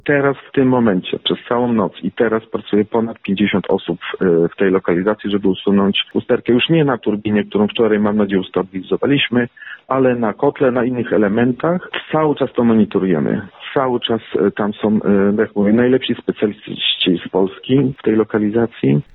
ROZMOWA DNIA